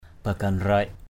/ba-ɡ͡ɣa-nraɪʔ/ (d.) dụng cụ đựng đồ lễ của đạo thầy Cả sư Balamon.